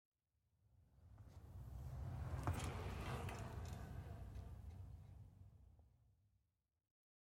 Звуки самоката
Звук проезжающего рядом самоката